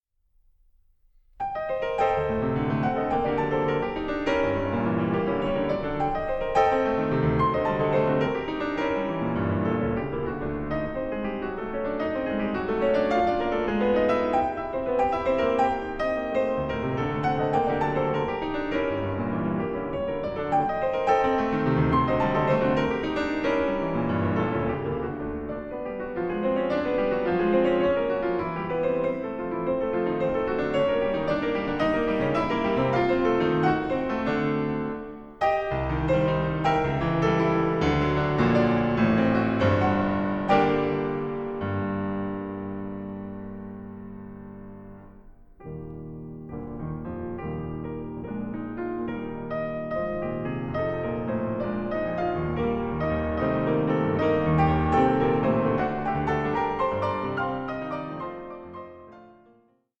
piano
By turns placid, sparse, restive and impassioned